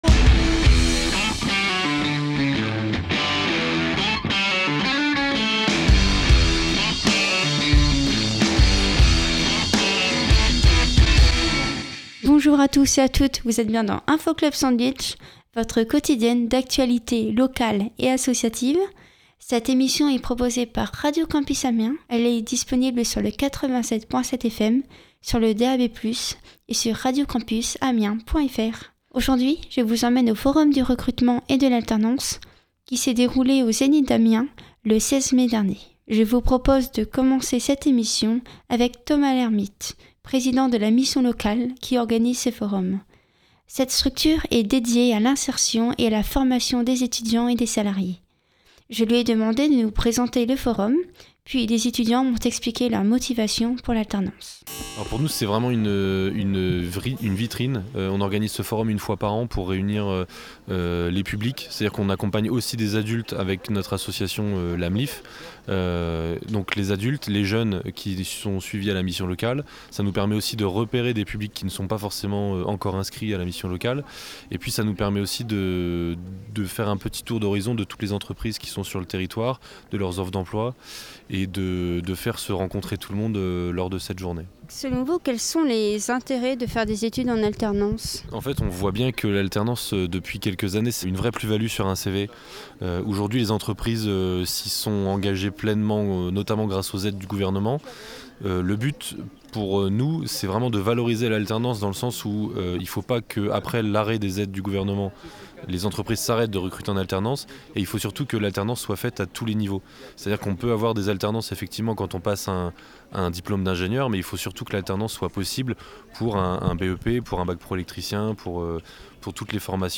Aujourd’hui nous vous emmènons au forum du recrutement et de l’alternance qui s’est déroulé au Zénith d’Amiens le 16 mai dernier.